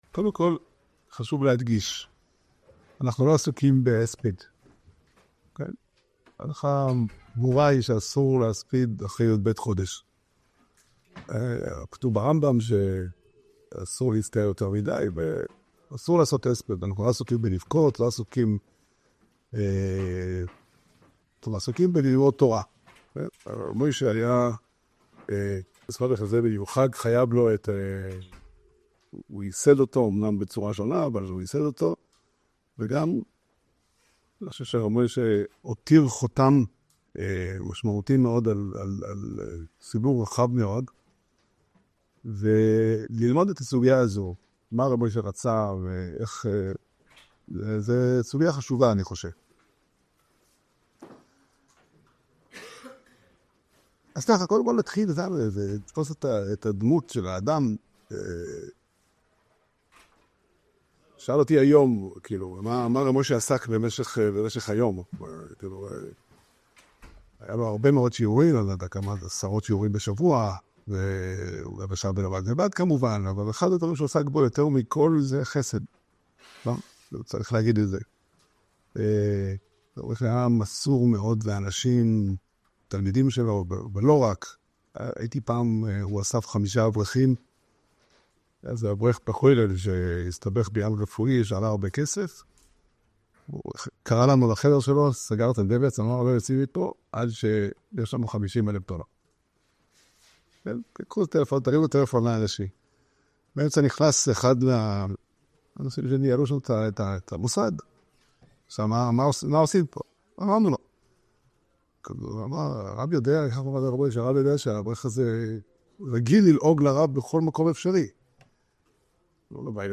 שיעור שנמסר בבית המדרש פתחי עולם בתאריך ט' טבת תשפ"ה